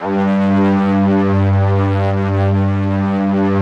SPCSTR. G2-L.wav